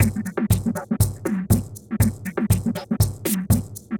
Watching (Drums) 120BPM.wav